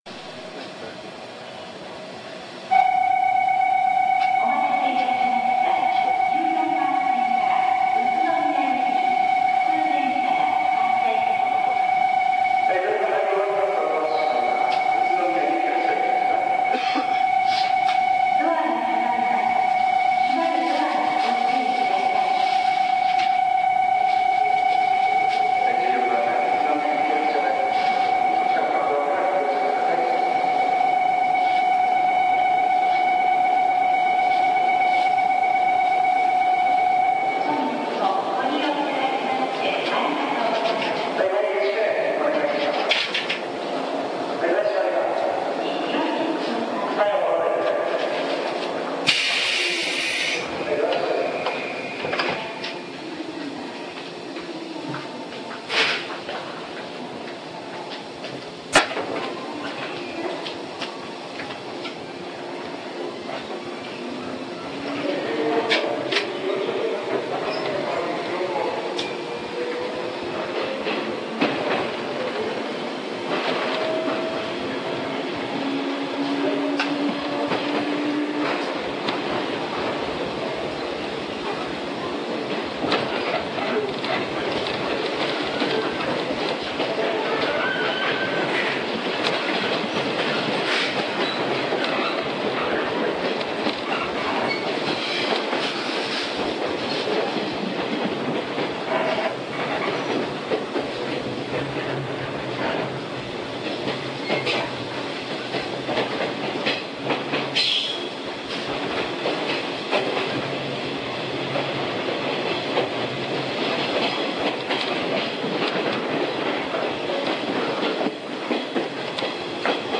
音の面でも113系などとほとんど変わりませんが、わかりやすいところではドアエンジンが異なっています。